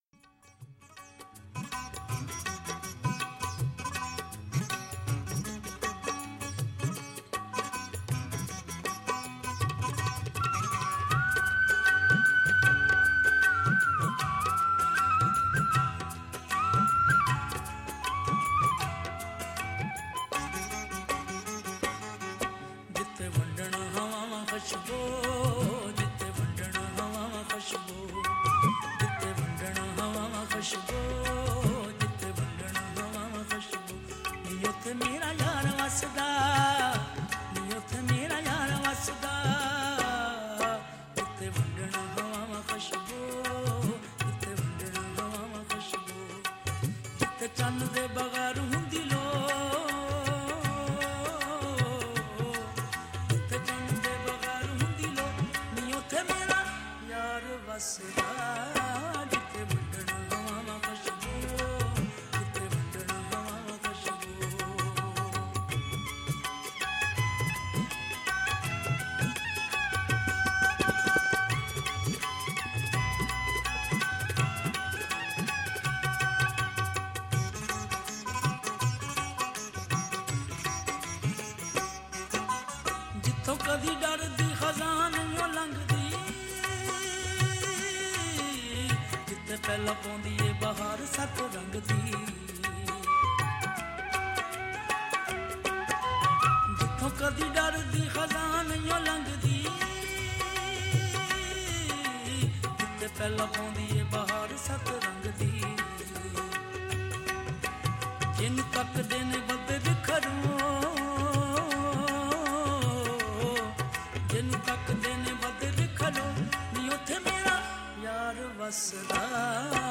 powerful and magical voice